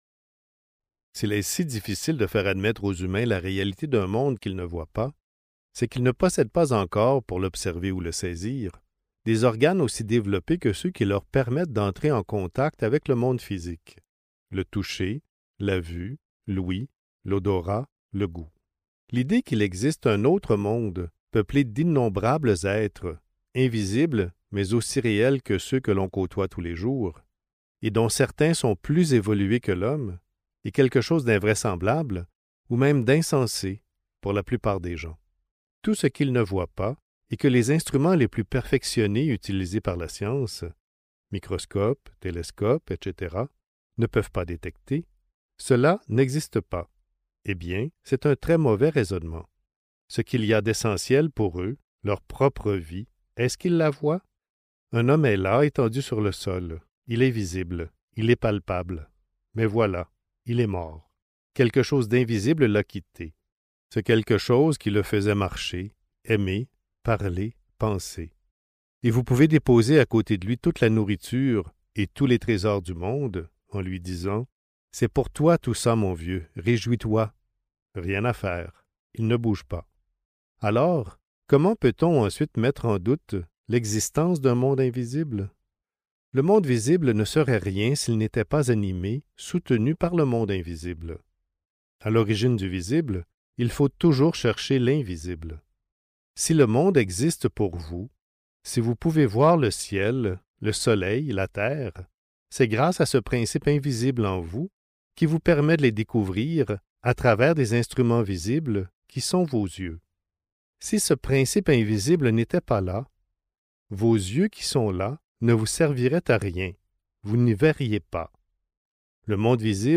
Regards sur l'invisible (Livre audio | CD MP3) | Omraam Mikhaël Aïvanhov